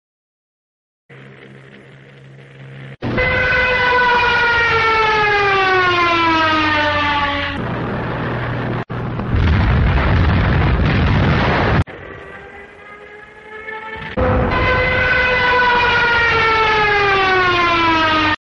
The Most Terrifying sound u can hear during ww2 ⚠educational⚠.